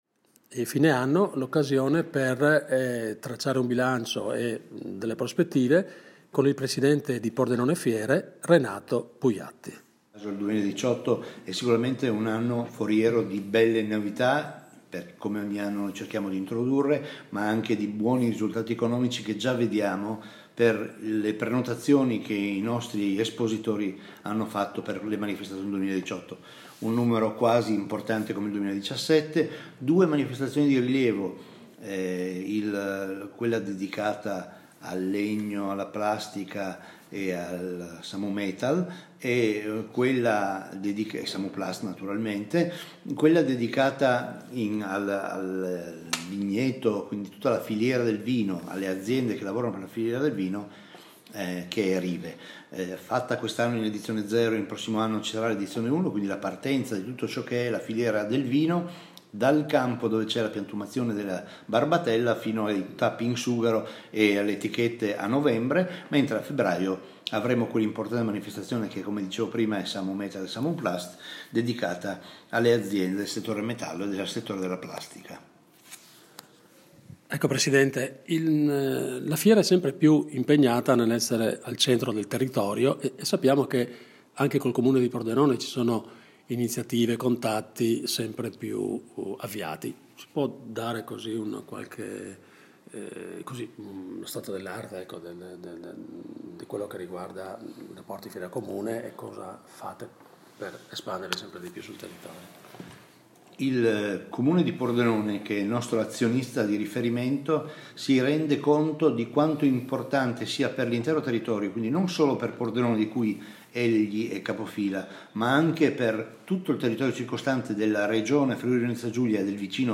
al consueto brindisi natalizio con la stampa, le categorie economiche e le istituzioni.